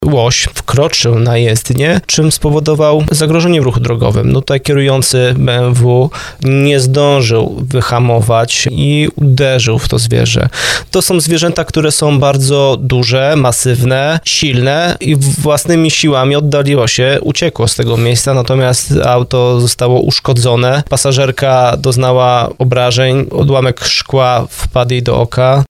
mówił w programie Pomagamy i Chronimy